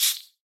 mob / silverfish / hit2.ogg
hit2.ogg